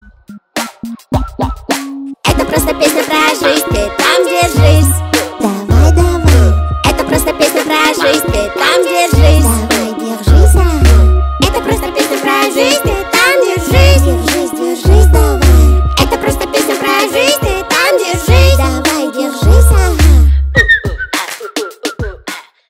весёлые